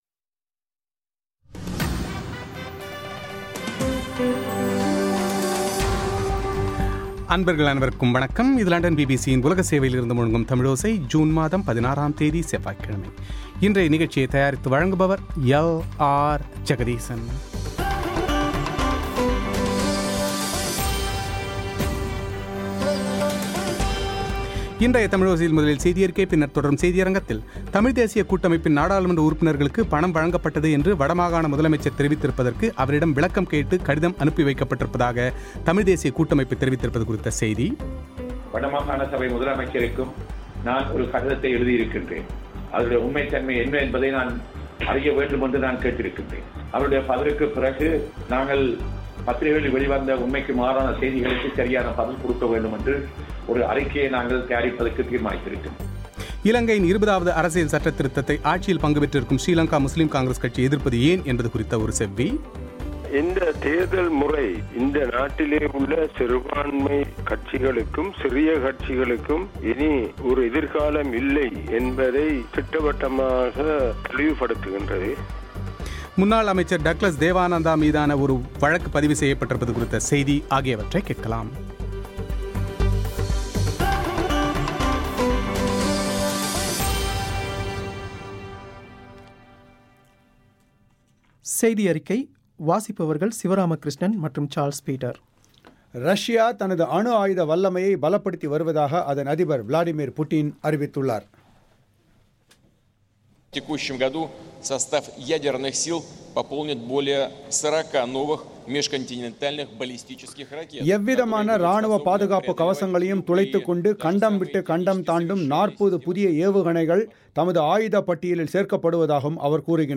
இலங்கையின் 20 ஆவது அரசியல் சட்டத்திருதத்தை ஆட்சியில் பங்குபெற்றிருக்கும் ஸ்ரீலங்கா முஸ்லீம் காங்கிரஸ் கட்சி எதிர்ப்பு தெரிவித்திருப்பது ஏன் என்பது குறித்து அக்கட்சியின் பொதுச் செயலர் ஹஸன் அலியின் செவ்வி;